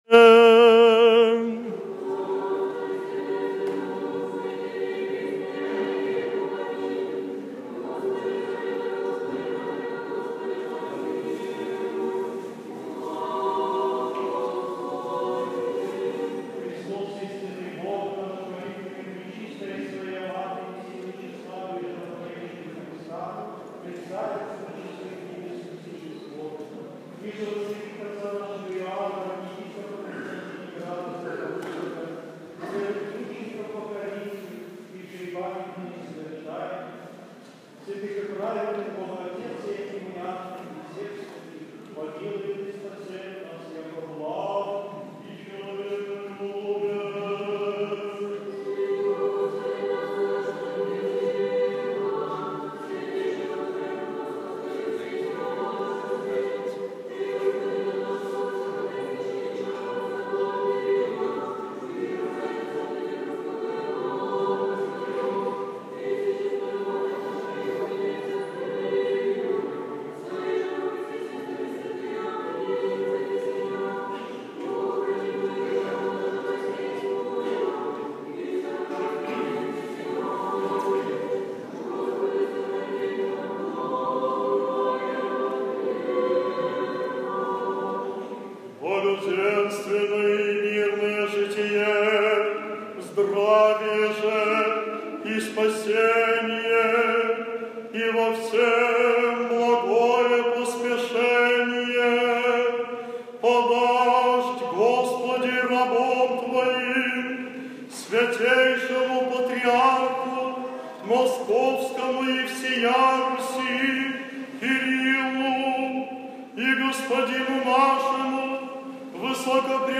14 августа 2015 года. Многолетия, напутственное слово и поклонение Кресту в престольный праздник на подворье Новодевичьего монастыря в Лисином корпусе (СПб)
Домой / Проповеди / Аудио-проповеди / 14 августа 2015 года.